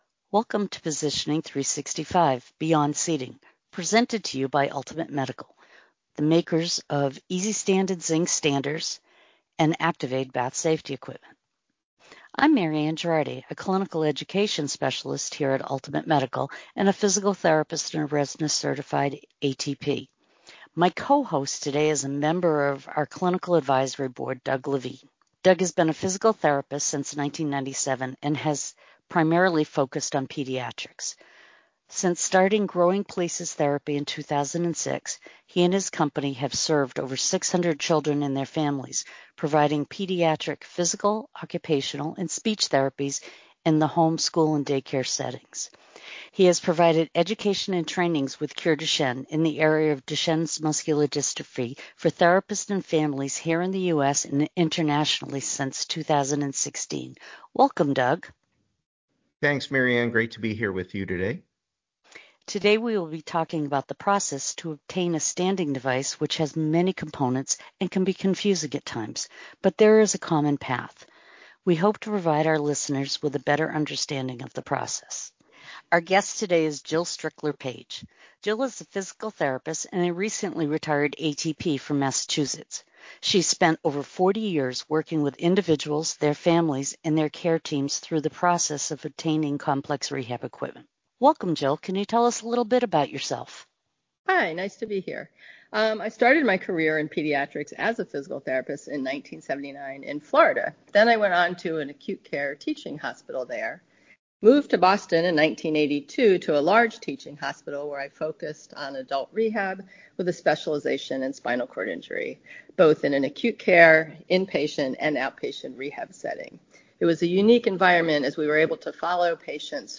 Together they review each step, including medical clearance, goal identification, equipment trials, documentation, and appeals. The episode also touches on how this journey differs between pediatric and adult clients, while noting the common principles that guide both. Throughout the conversation, they stress the value of a collaborative, team-based approach to ensure optimal recommendations and outcomes.